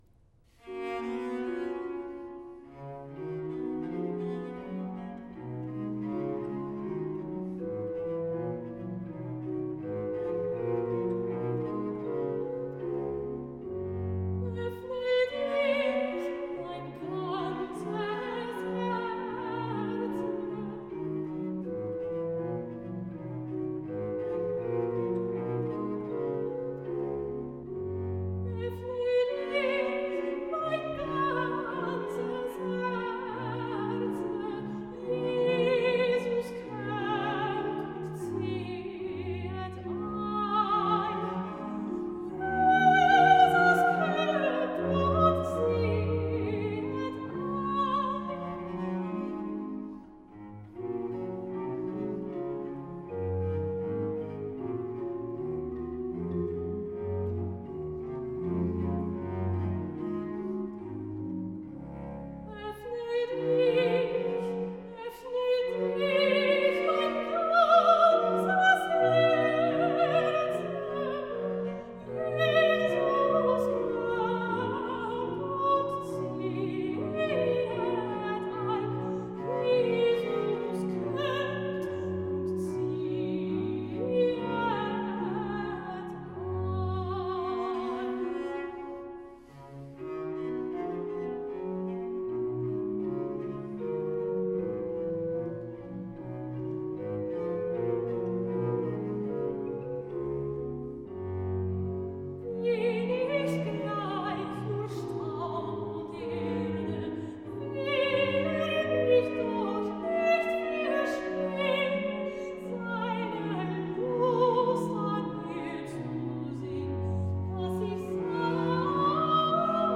17-bach-js_-nun-komm-der-heiden-heiland-aria_-offne-dich-mein-ganzes-herze.m4a